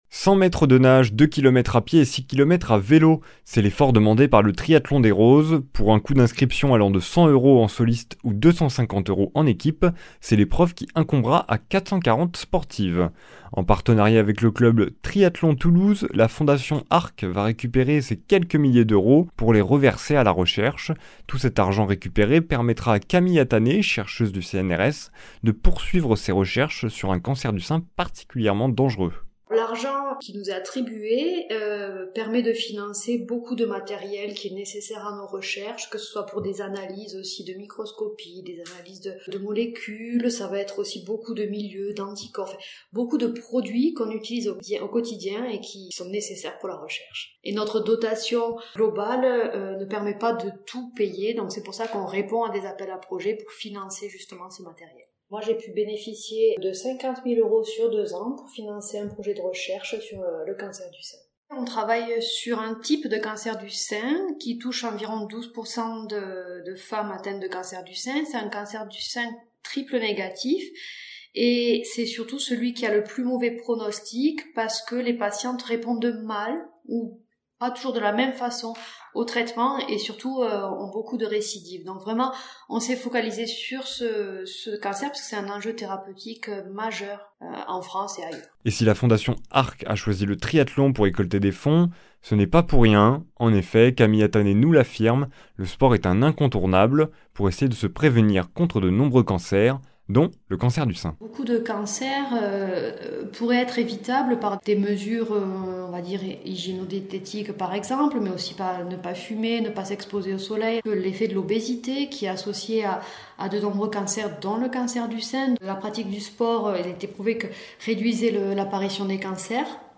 vendredi 22 septembre 2023 Journal d’information régional Durée 6 min